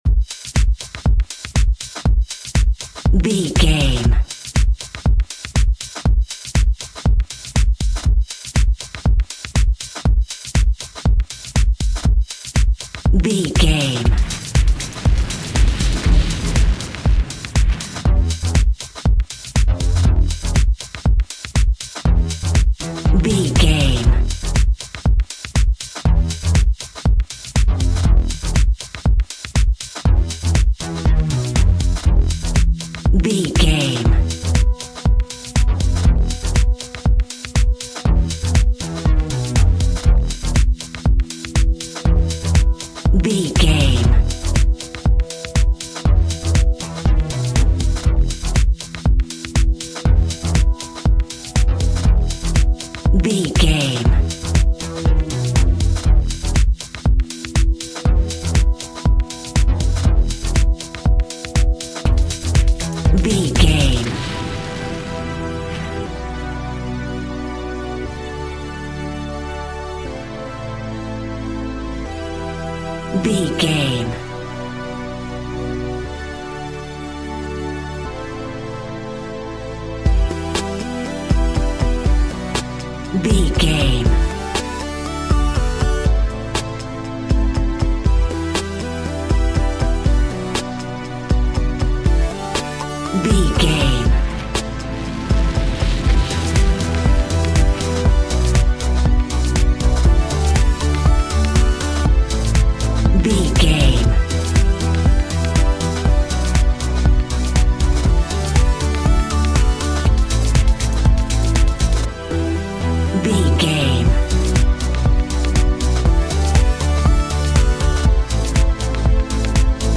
Aeolian/Minor
Fast
groovy
uplifting
driving
energetic
drums
synthesiser
drum machine
electro house
house music